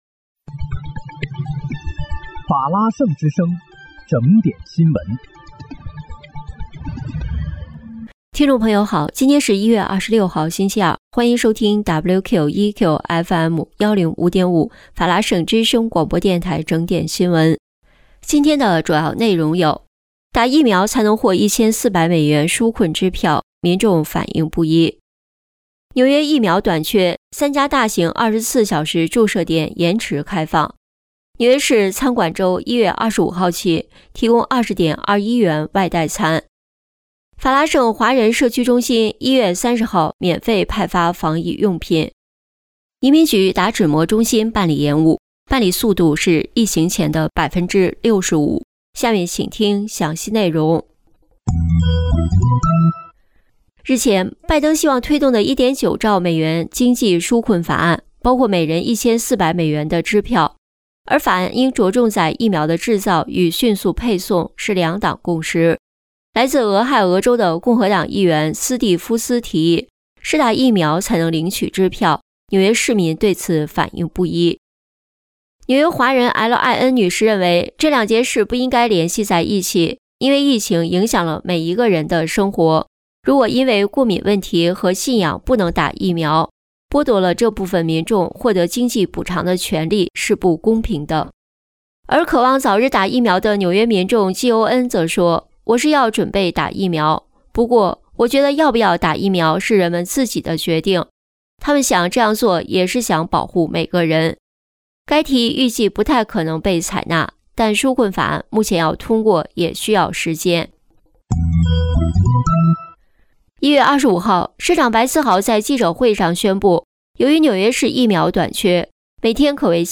1月26日（星期二）纽约整点新闻